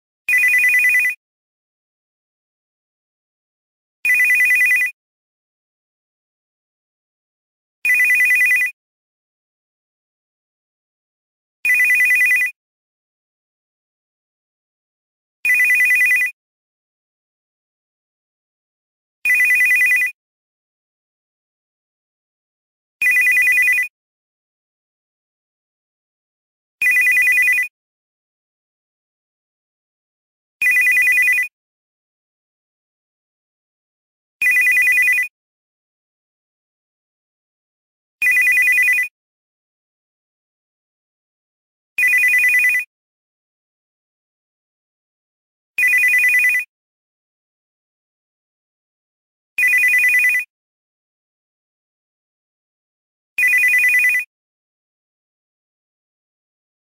• Качество: 320, Stereo
Electronic
без слов
звонкие
пищалка